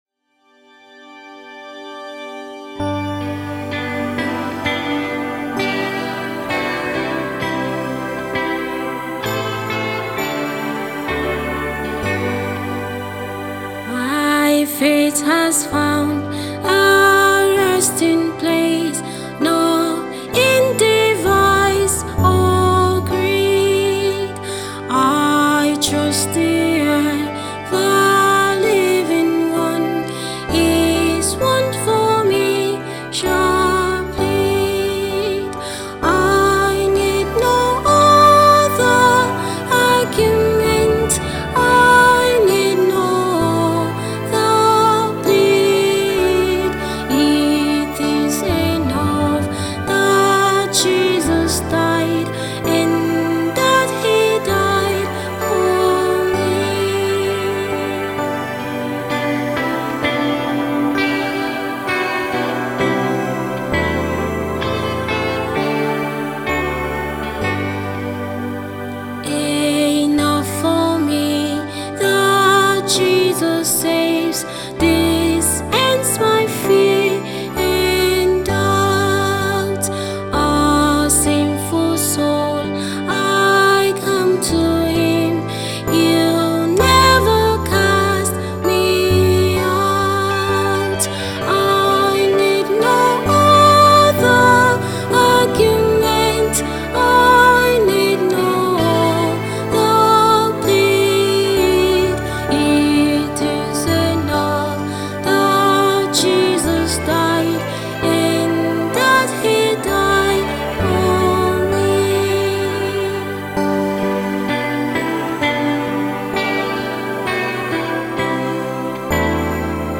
I’m blessed with this awesome timely hymn.